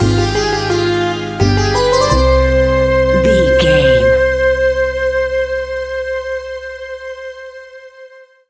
Aeolian/Minor
World Music
percussion
congas
bongos
djembe